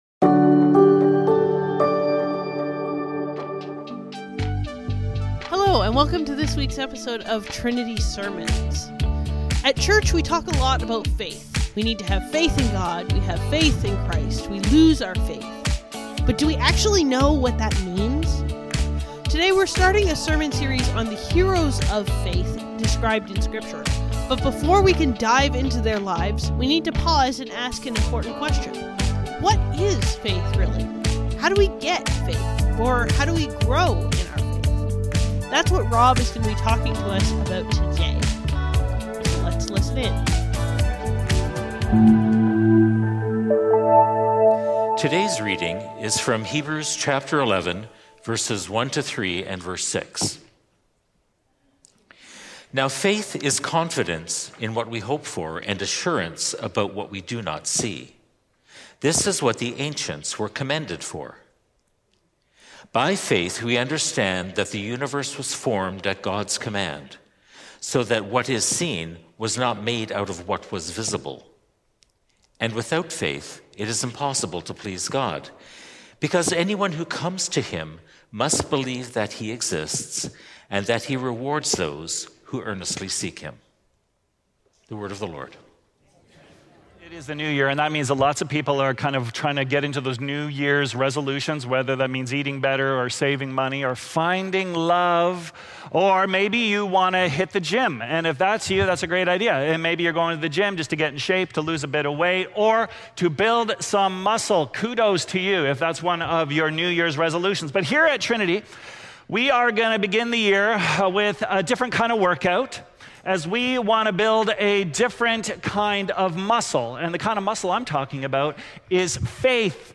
Trinity Streetsville - Flex Your Faith | Heroes of Faith | Trinity Sermons